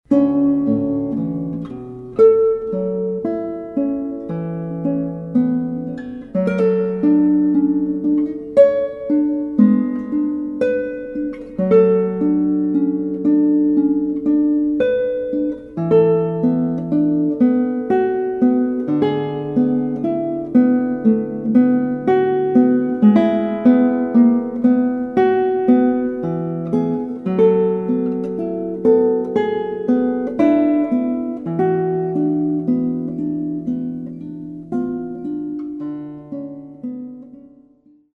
intimacies of color and liquid tone."